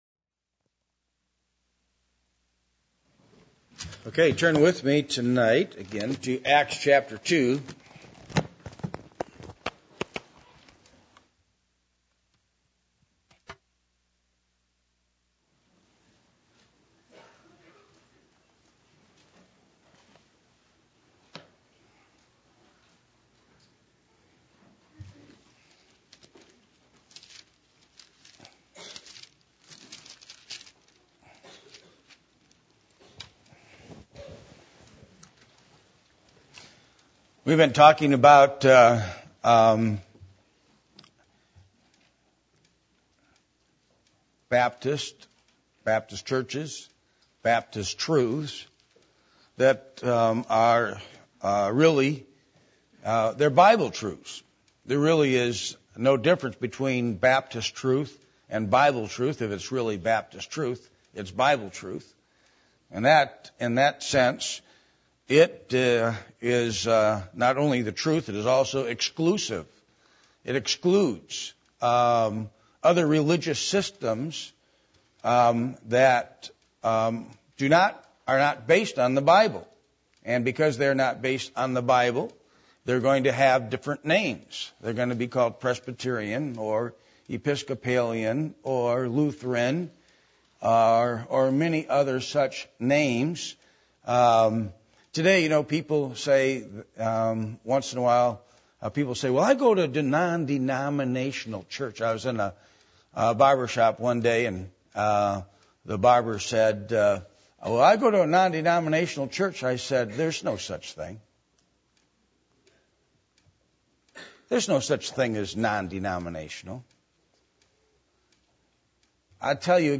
Passage: Acts 2:41-42 Service Type: Midweek Meeting %todo_render% « The Answer To The Worker Problem The Cross Of Christ Is Victory